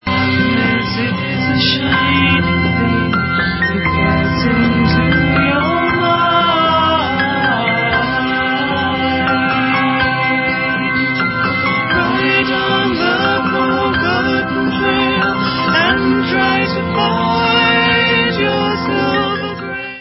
LOST CLASSIC OF 60'S UK ACID FOLK/BAROQUE PSYCHEDELICA